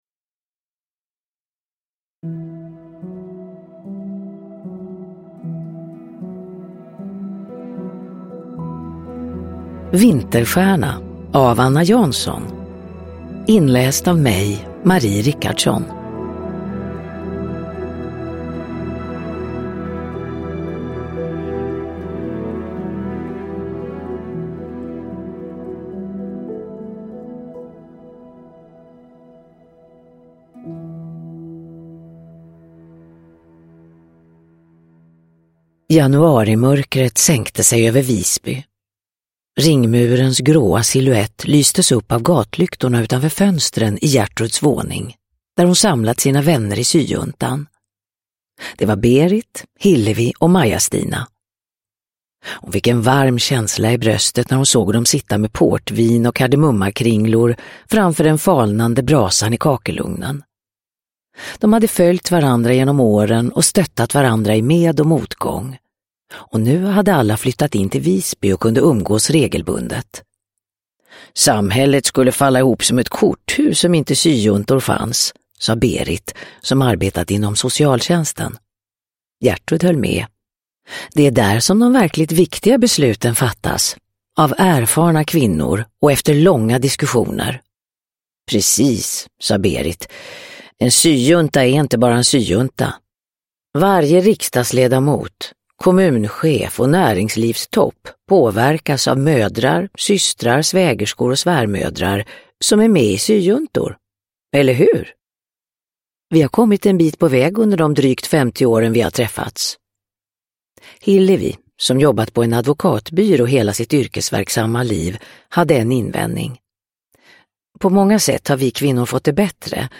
Vinterstjärna – Ljudbok
Uppläsare: Marie Richardson